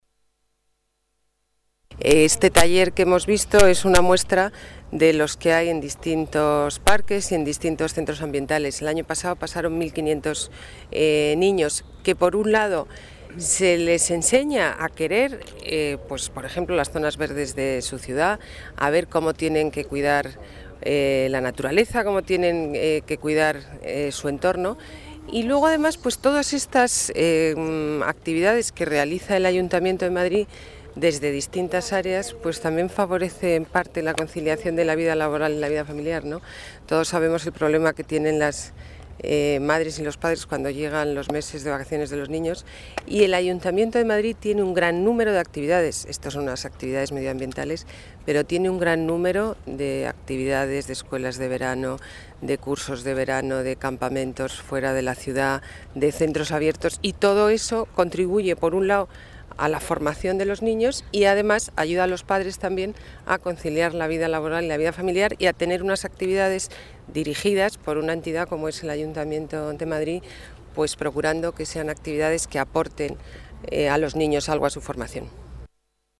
Nueva ventana:Declaraciones de la delegada de Medio Ambiente, Ana Botella, durante su visita a los talleres de la Escuela de Verano 2008